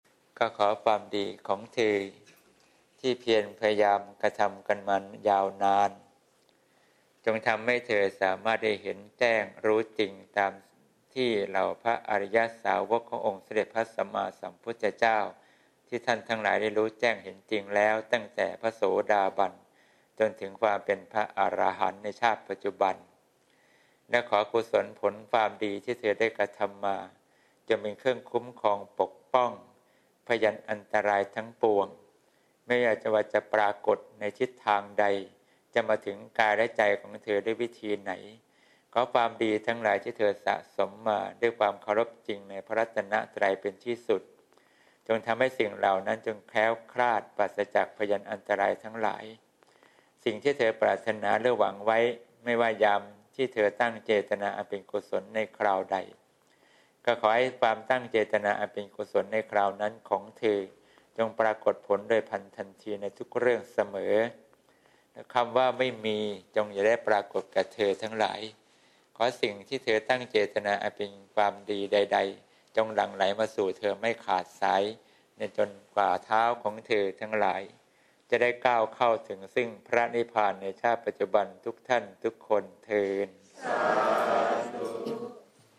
หลวงพ่อให้พร : วันที่ ๓๐ มีนาคม ๒๕๖๘